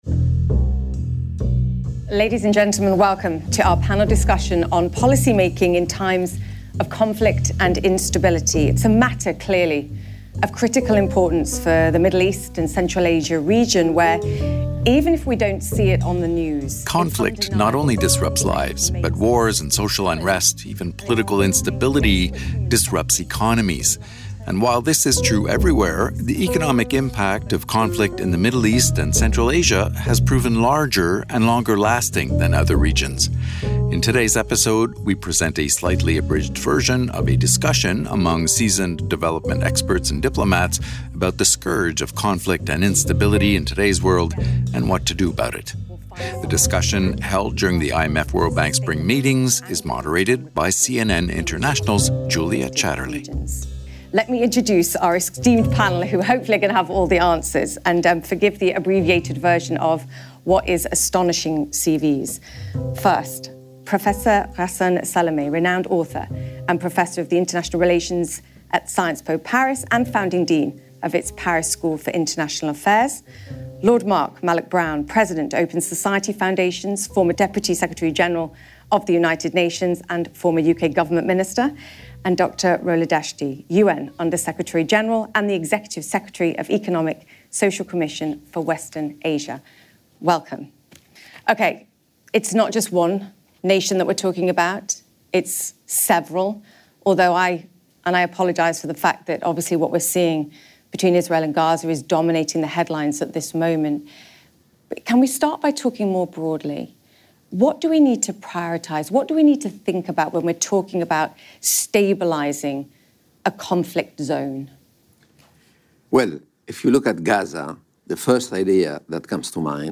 Conflict disrupts lives and economies everywhere, but recent IMF analytical work suggests the economic impact of conflict in the Middle East and Central Asia has proven larger and more persistent than in other regions. In this podcast, Ghassan Salamé (SciencesPo Paris), Mark Malloch-Brown (Open Society Foundations), and Rola Dashti (UNESCWA) discuss how the recent scourge of conflict and instability requires innovative thinking. The panel was held during the IMF-World Bank Spring Meetings and moderated by CNN International’s Julia Chatterley.